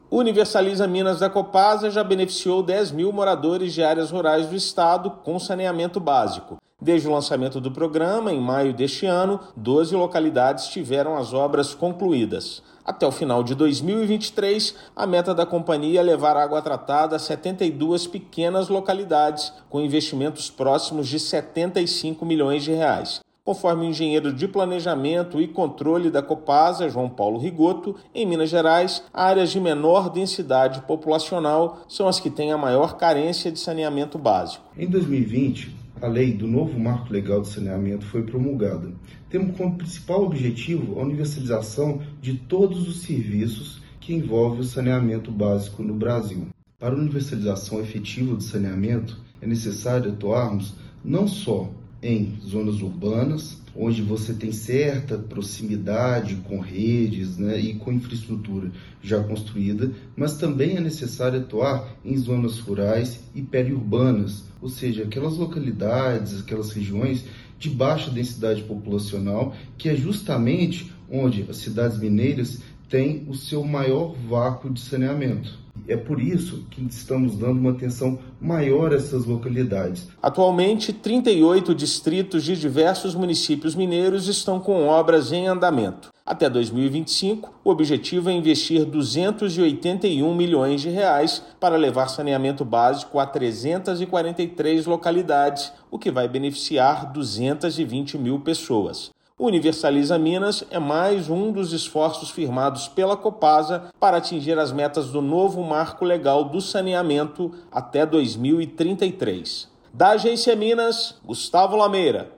Meta da Copasa é levar água tratada a 72 pequenas localidades com investimentos de cerca de R$ 75 milhões até o final de 2023. Ouça matéria de rádio.